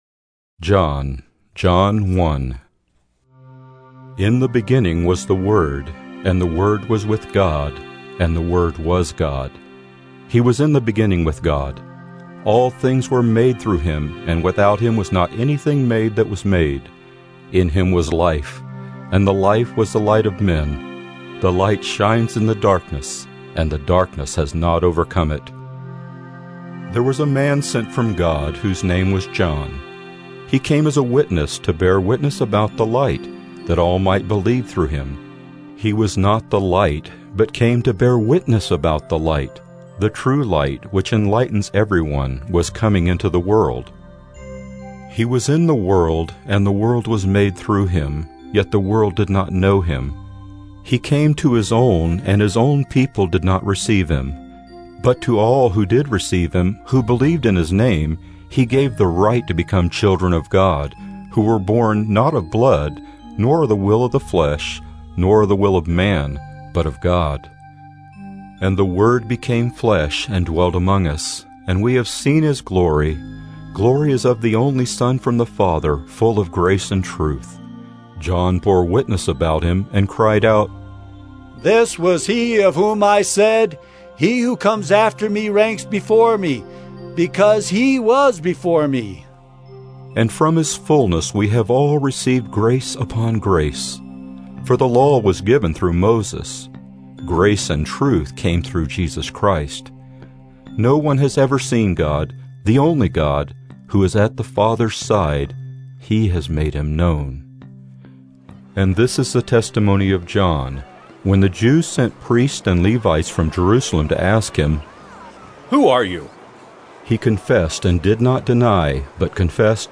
Dramatised Audio Book – Gospel of John